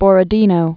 (bôrə-dēnō, bŏr-, bə-rə-dyē-nô)